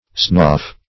Search Result for " snoff" : The Collaborative International Dictionary of English v.0.48: Snoff \Snoff\ (sn[o^]f; 115), n. [Cf. Snuff .]